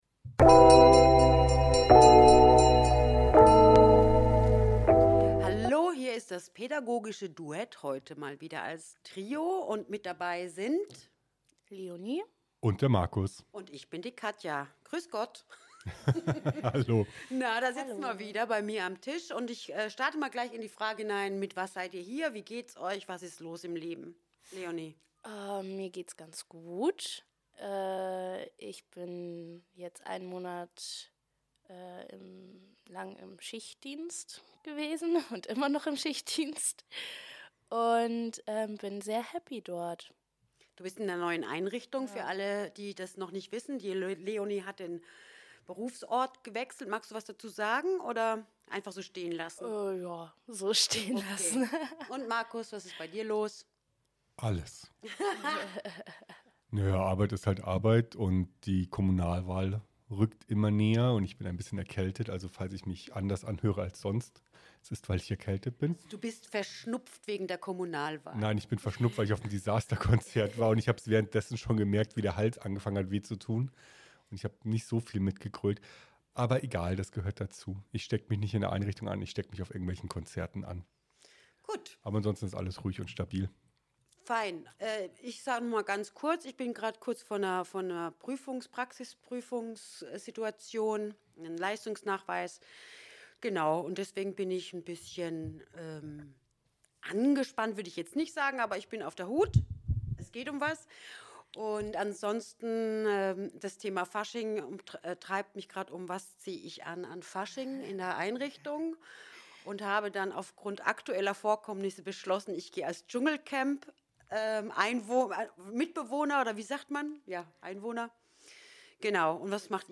Jedes Mitglied des Trios teilt seine persönlichen Ansichten und Erfahrungen mit unterschiedlichen Gesprächsformaten und der Dynamik, die zwischen Eltern und Erziehern entsteht.